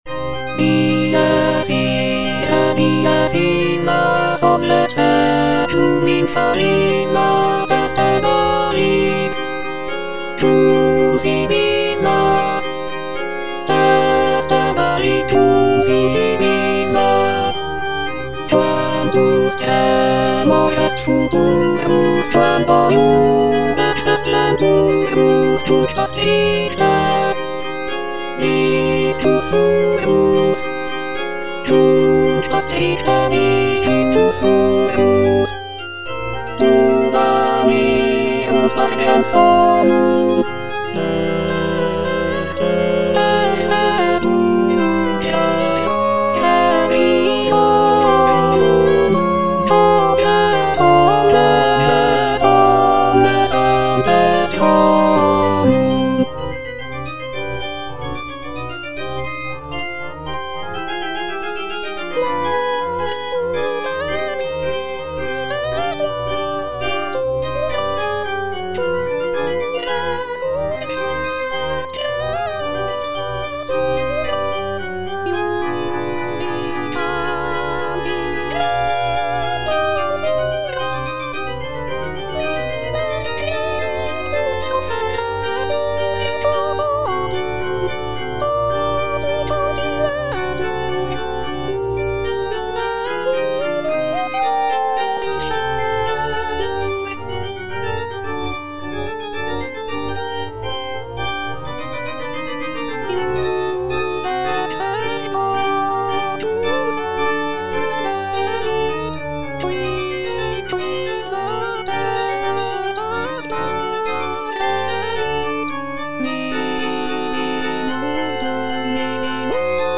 Alto (Emphasised voice and other voices) Ads stop
choral composition